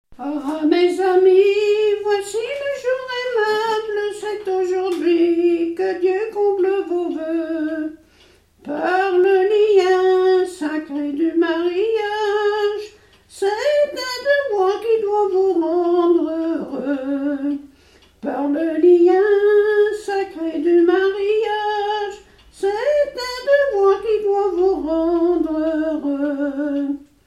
Usage d'après l'informateur circonstance : fiançaille, noce
Genre laisse
Catégorie Pièce musicale inédite